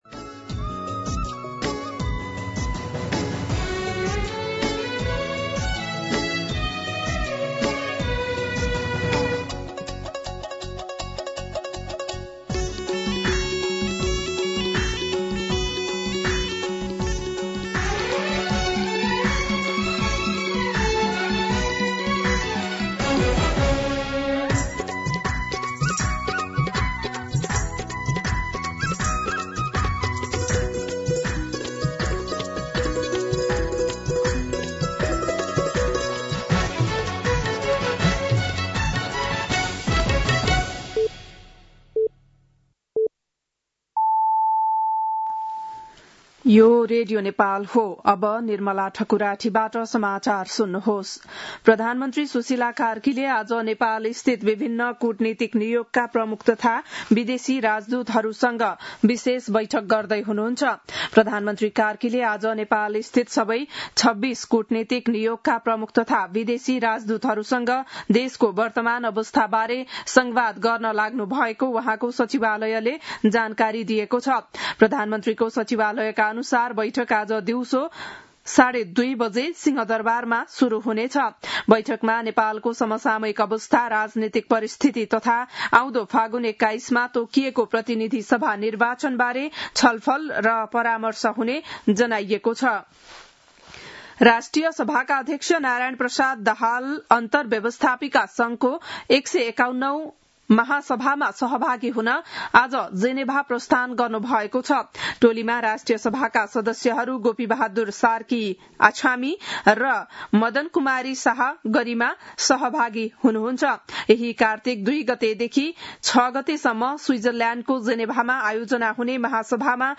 बिहान ११ बजेको नेपाली समाचार : ३१ असोज , २०८२
11-am-Nepali-News-6.mp3